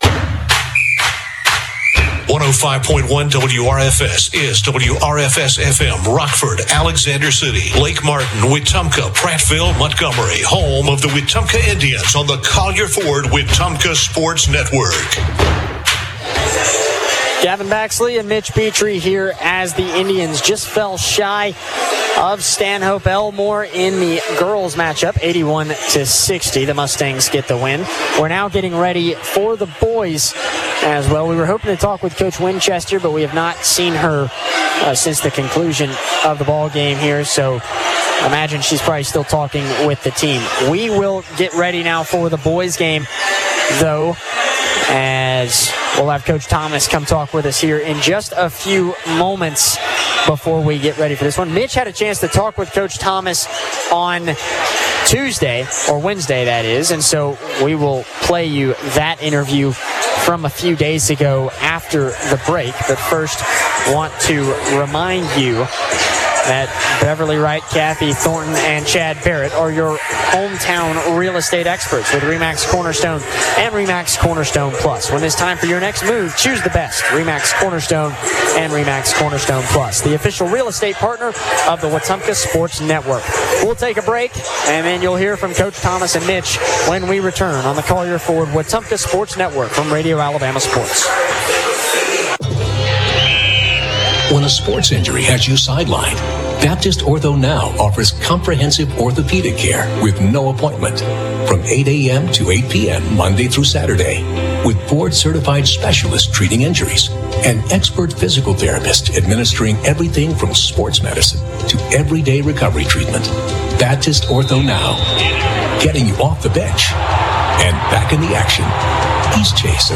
call Wetumpka's game against Stanhope Elmore. The Indians won 60-53.